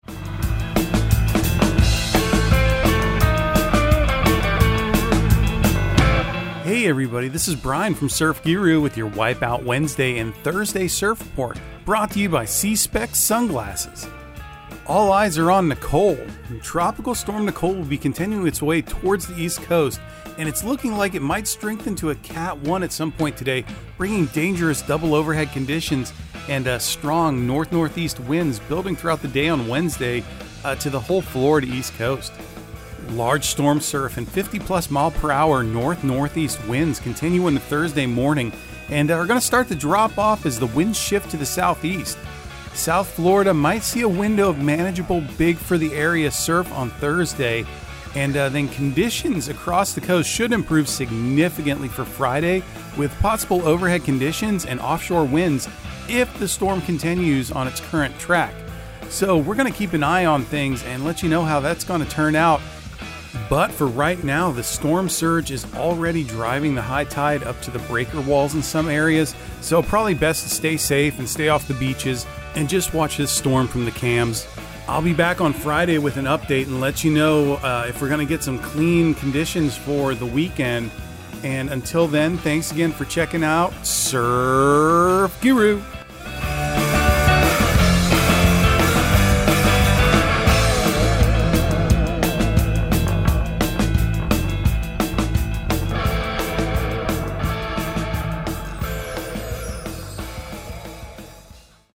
Surf Guru Surf Report and Forecast 11/09/2022 Audio surf report and surf forecast on November 09 for Central Florida and the Southeast.